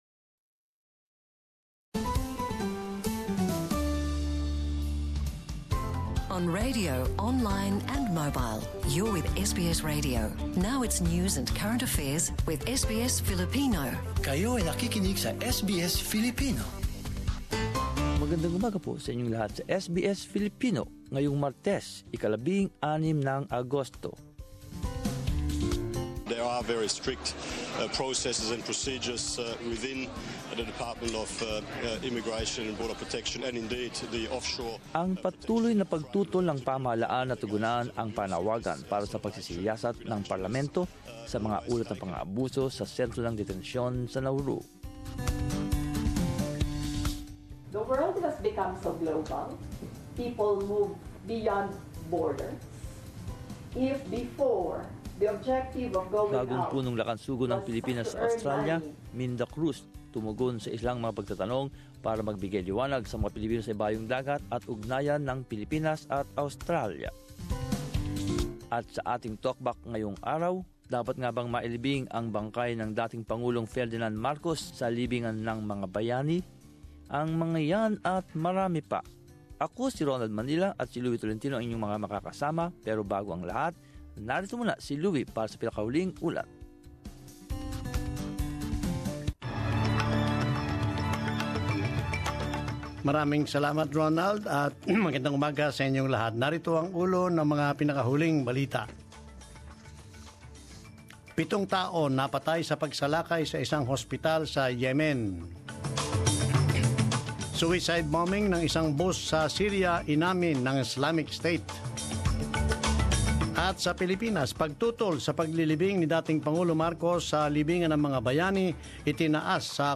Talkback. Listen to the comments of our listeners on this divisive issue among the Filipinos Image